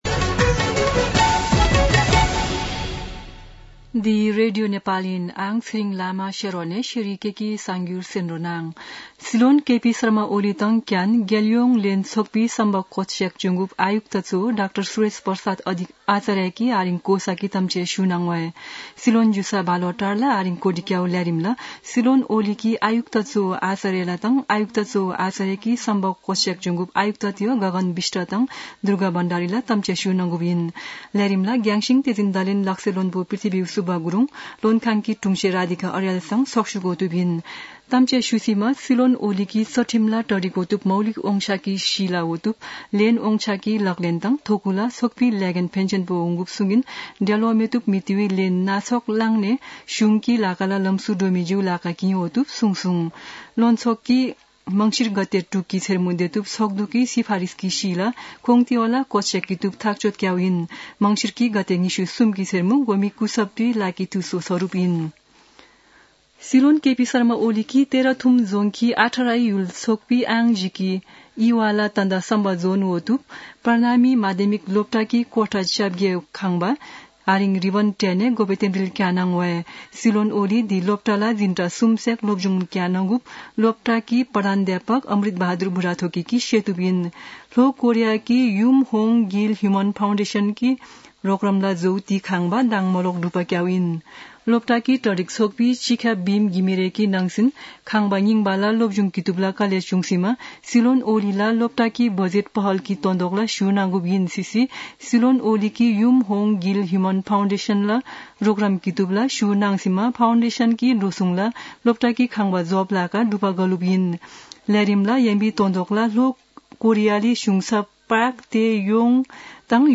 शेर्पा भाषाको समाचार : २७ मंसिर , २०८१
4-pm-Sherpa-news-1-4.mp3